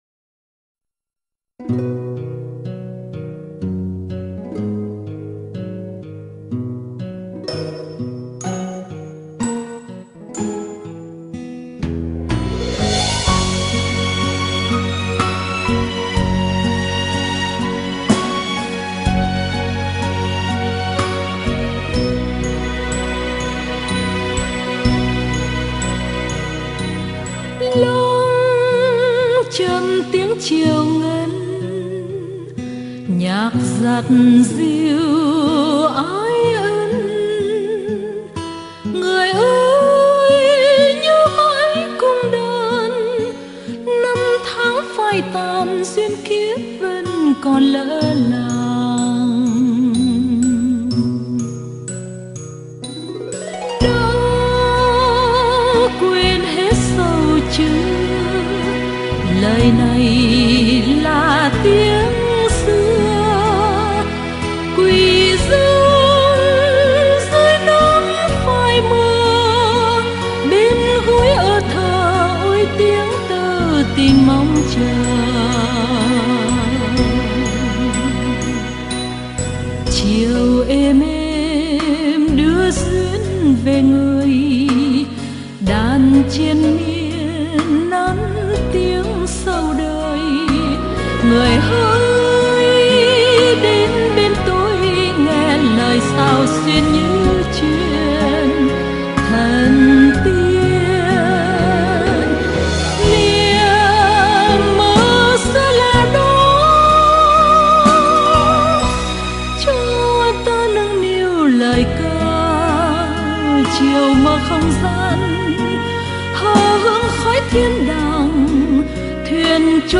Nữ Danh Ca